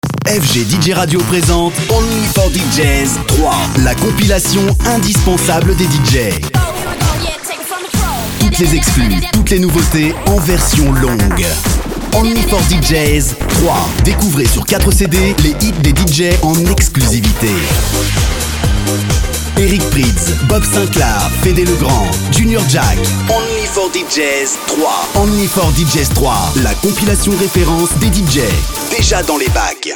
Sprecher französisch Voix off tonique, médium-grave, ou autre selon votre demande ...
Sprechprobe: Industrie (Muttersprache):